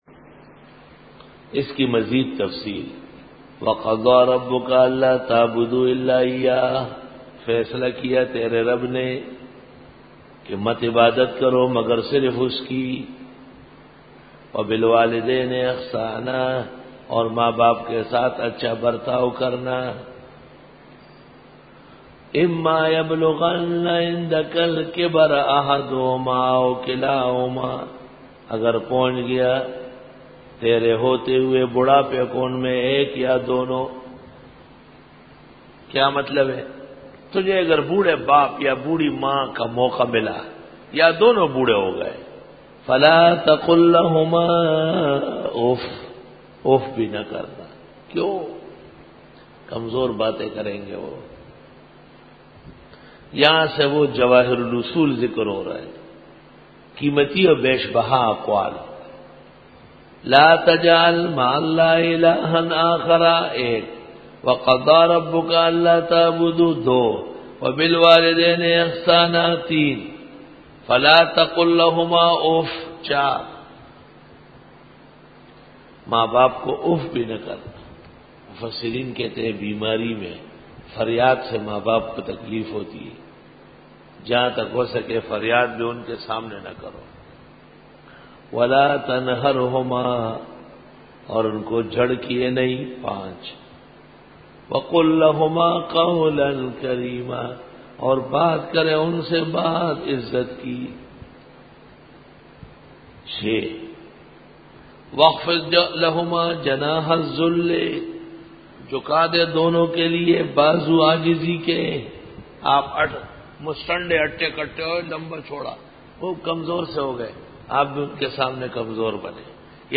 Dora-e-Tafseer 2008